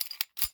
sounds_pistol_cock_02.ogg